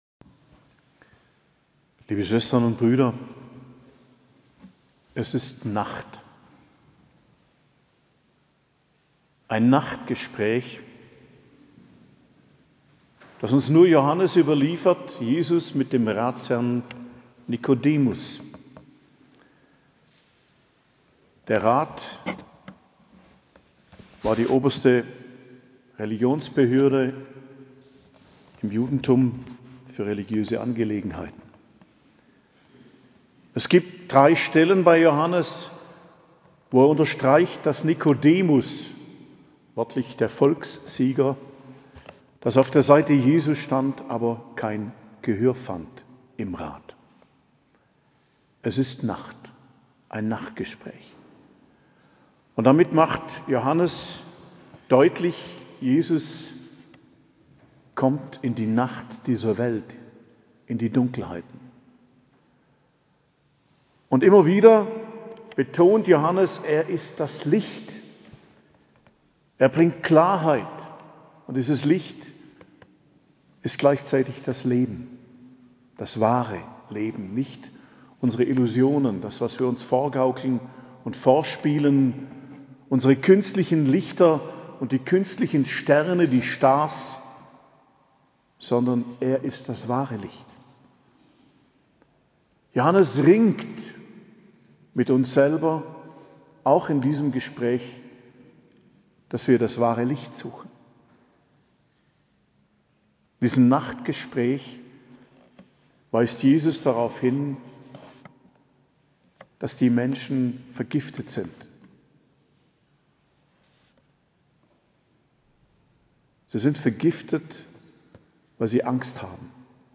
Predigt zum 4. Fastensonntag, 10.03.2024